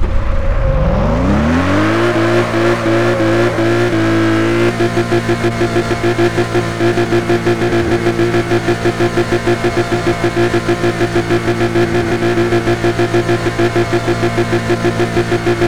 Index of /server/sound/vehicles/lwcars/lotus_esprit
rev.wav